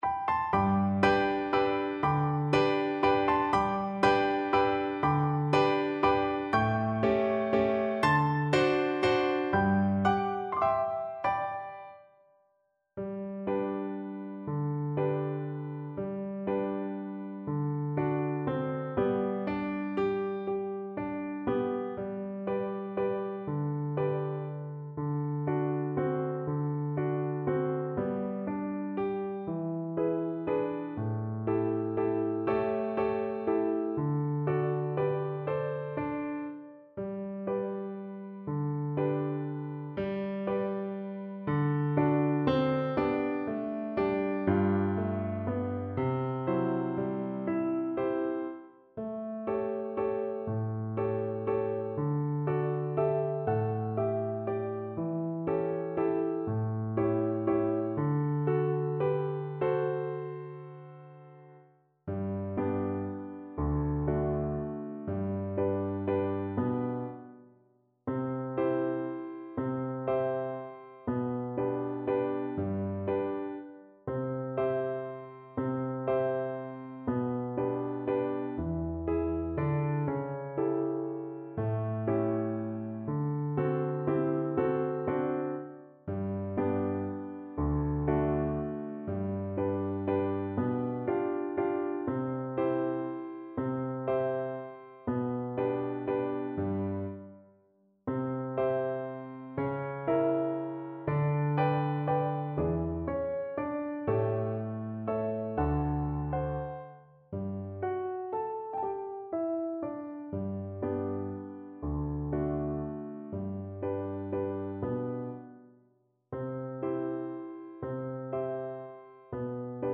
Valse moderato espressivo = 120
3/4 (View more 3/4 Music)